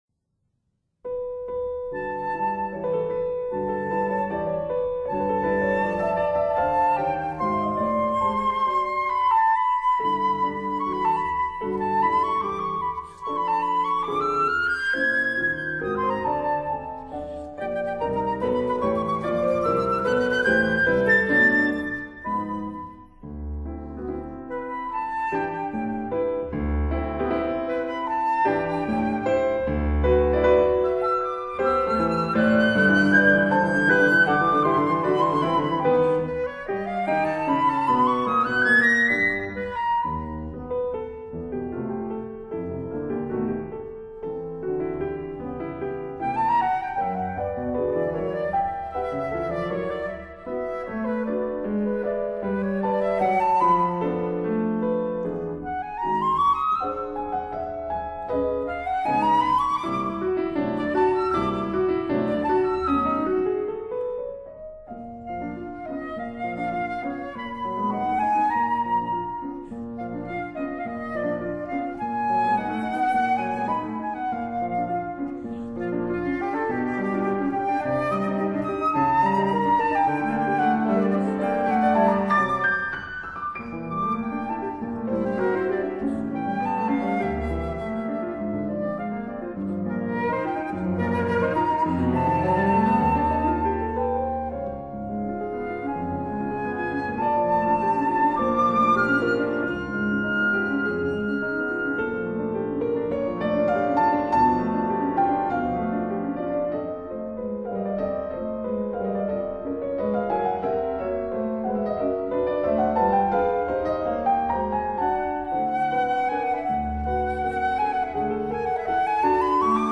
flute
piano